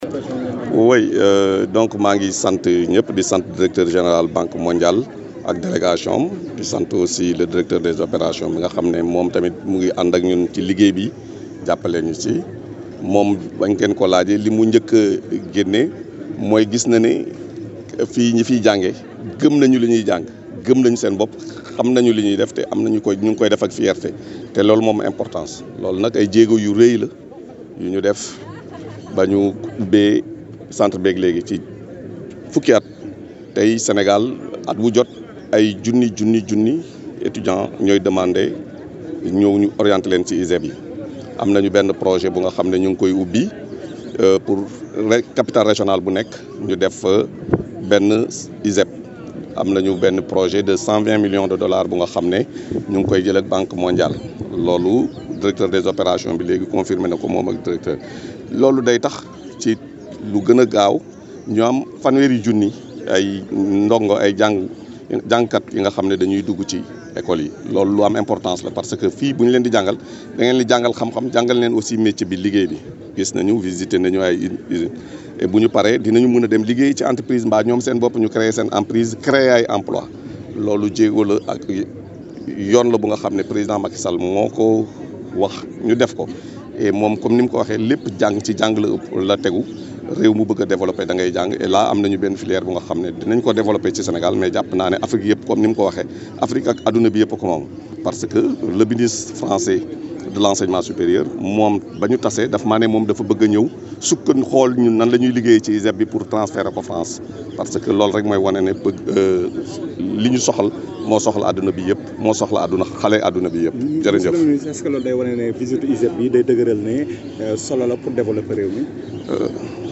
En visite à l’ISEP de Thiès ,le directeur général des opérations de la banque mondiale Axel Van Trotsenburg est bien séduit par le modèle ISEP, c’est pourquoi la banque mondiale compte débloquer 120millions de dollars pour construire 8 autres Isep dans le pays .Le Ministre de l’enseignement supérieur ,de la recherche et de l’innovation Cheikh Oumar Anne explique (extrait )